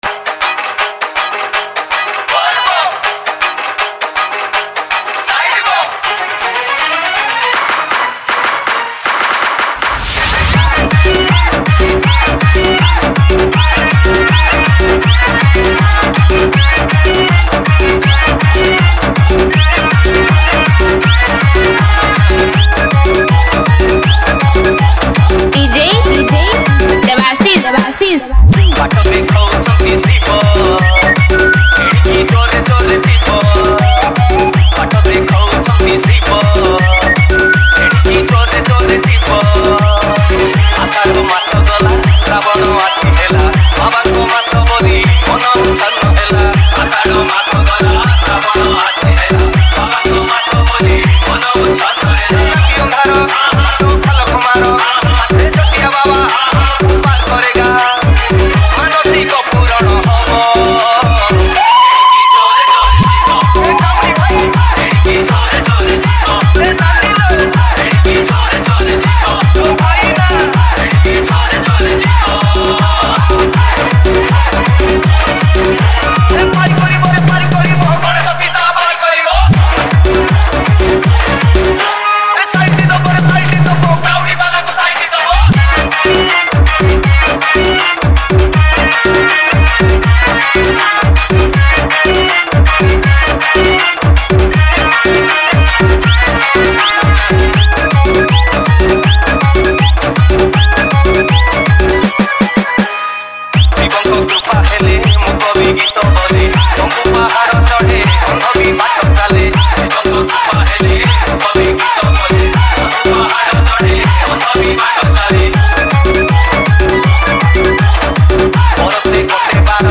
Category:  Odia Bhajan Dj 2019